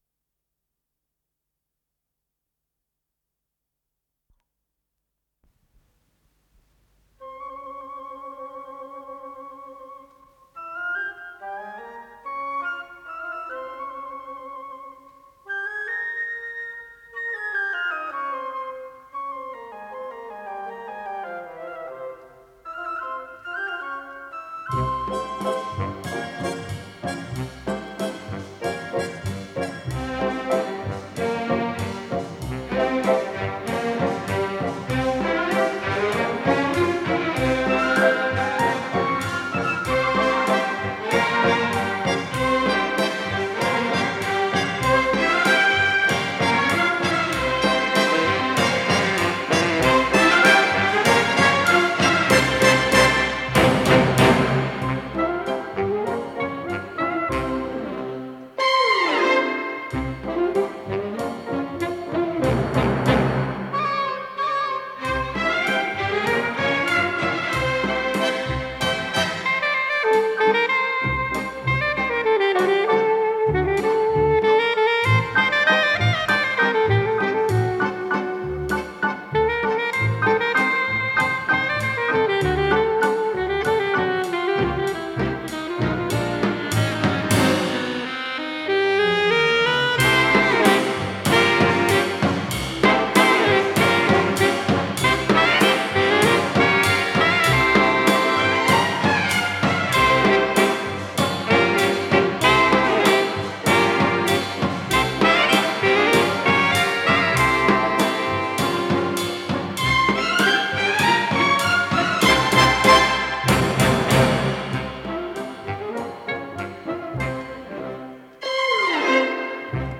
с профессиональной магнитной ленты
ПодзаголовокПьеса для эстрадного оркестра, фа мажор